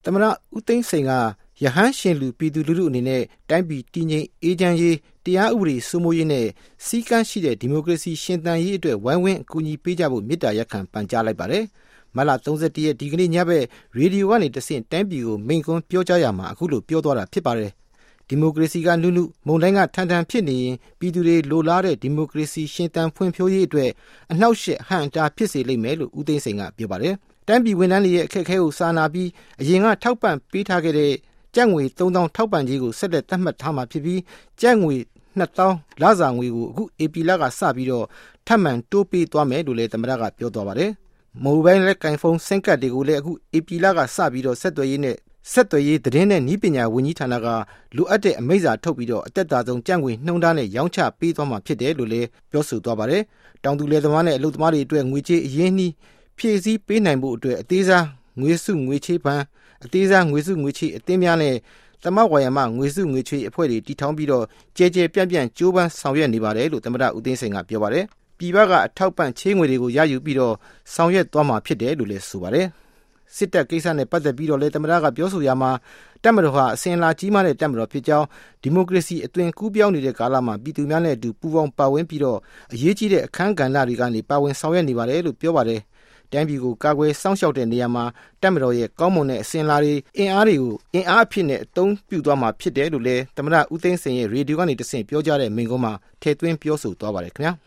U Thein Sein Speech